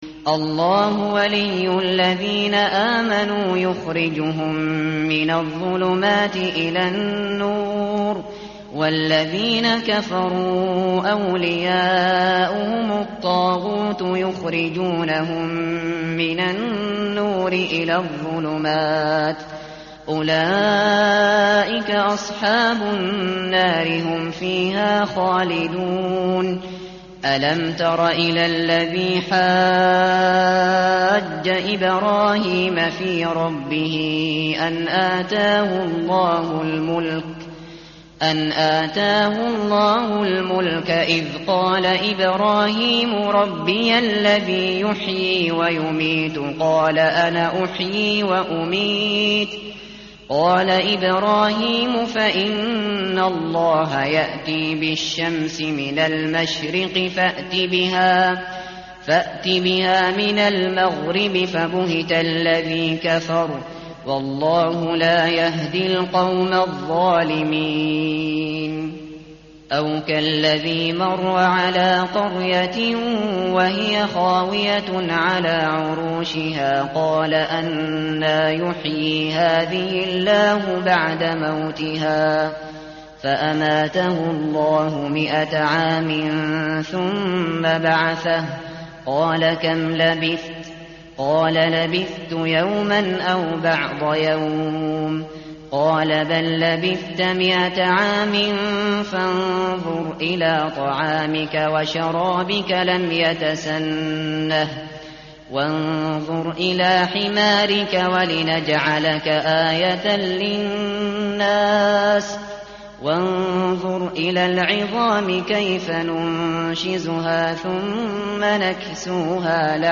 tartil_shateri_page_043.mp3